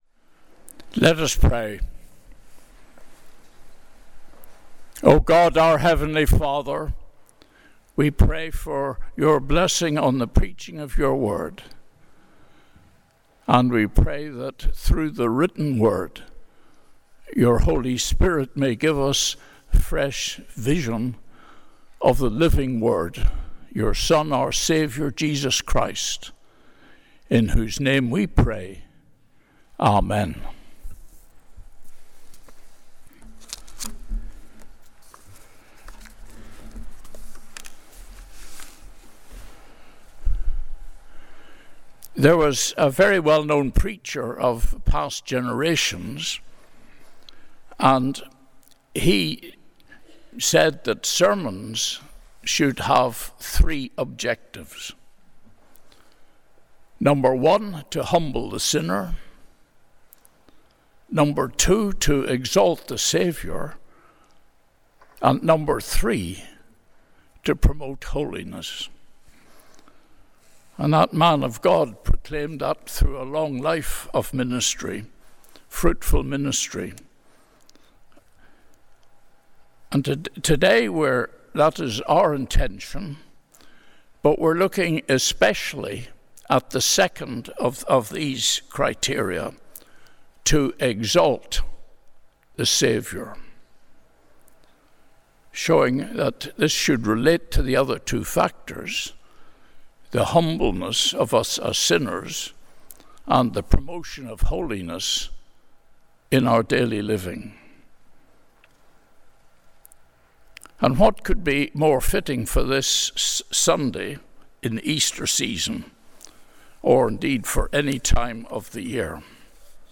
Sunday Worship–April 27, 2025
Sermons